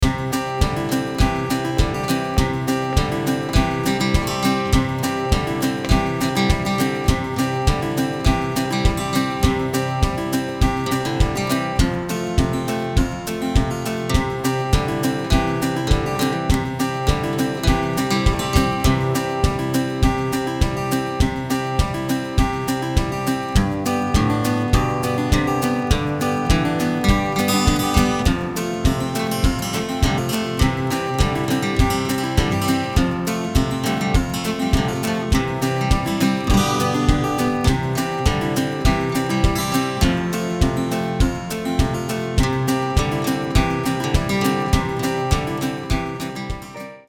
vg2acoustic.mp3